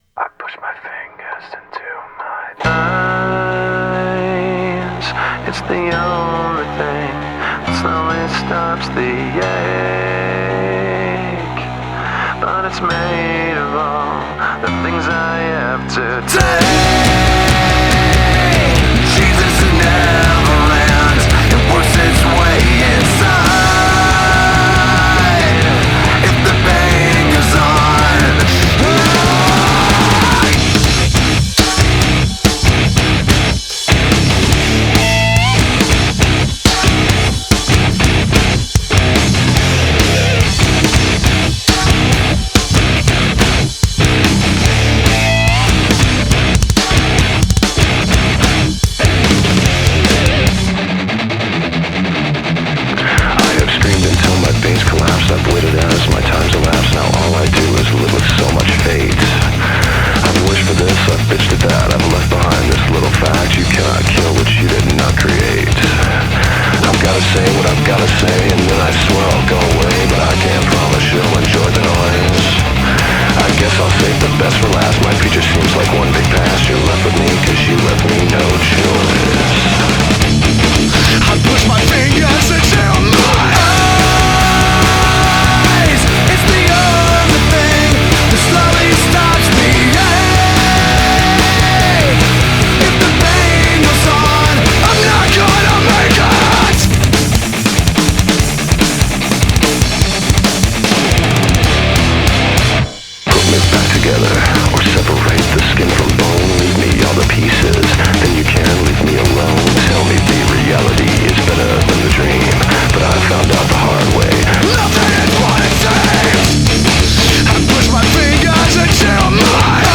2025-01-03 17:25:23 Gênero: Rock Views